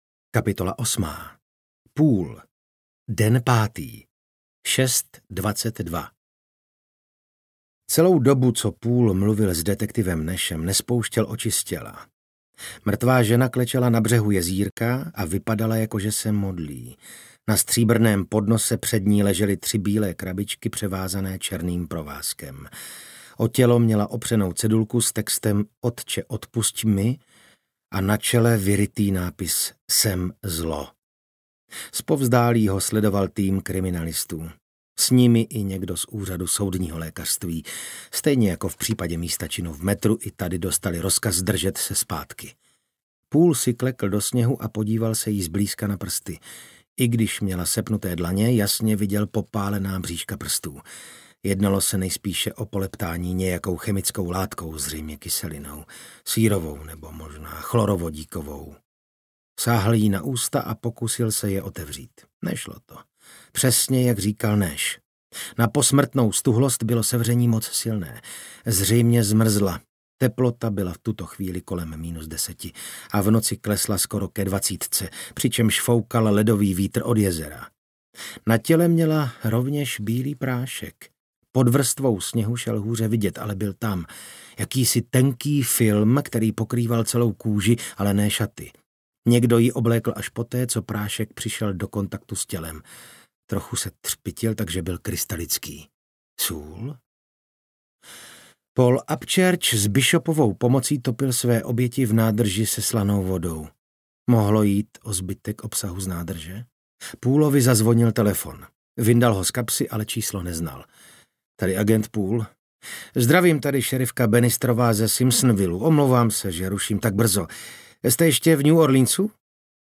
Audiobook
Read: Vasil Fridrich